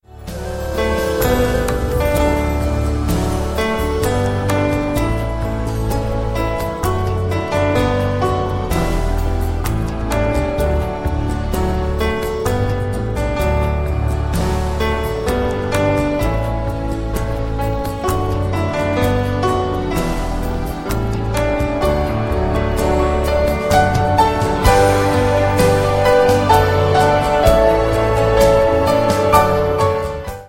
• Sachgebiet: Instrumental